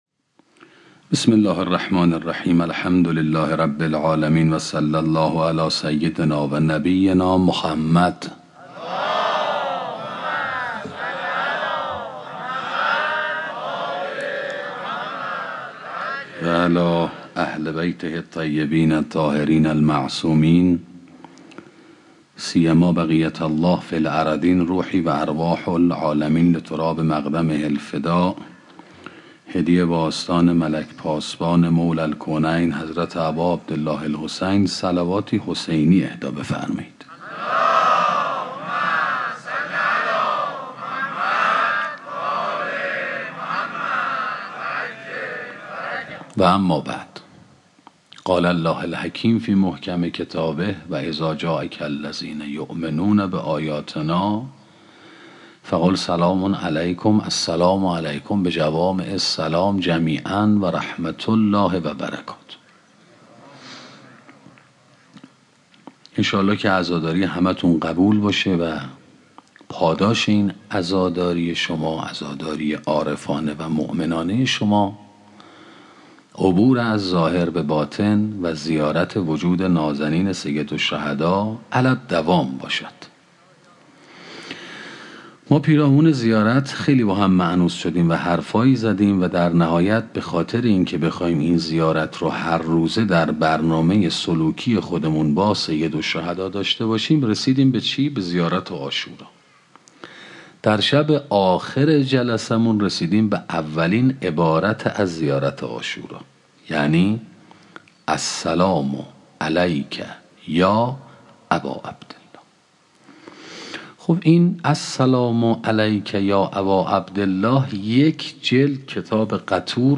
سخنرانی شرح زیارت عاشورا 10